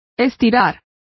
Complete with pronunciation of the translation of crane.